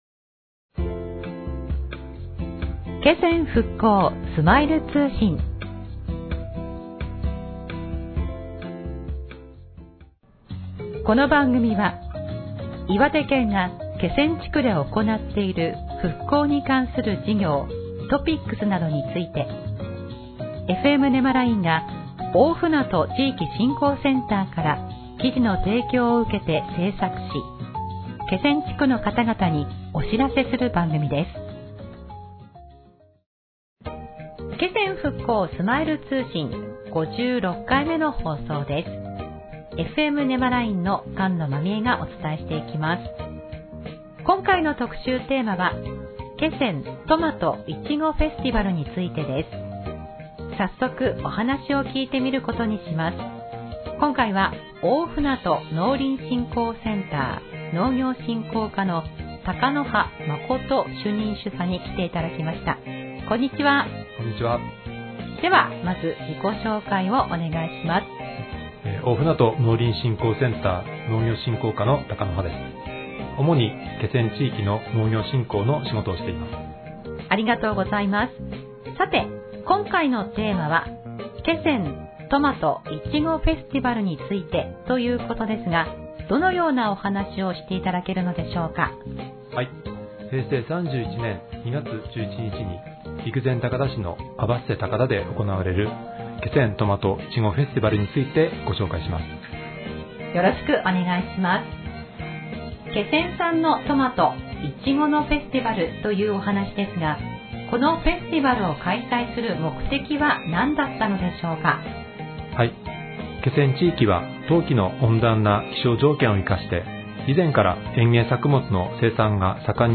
【パーソナリティ】FMねまらいん